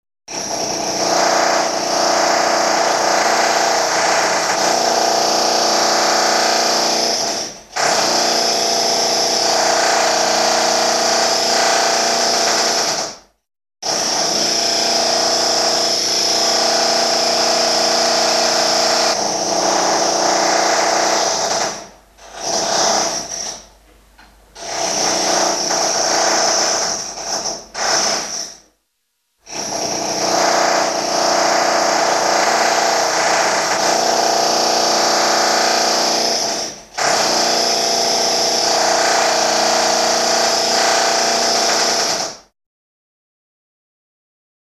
1 Шум дрели и/или перфоратора MP3 / 699 Кб [
01-drill.mp3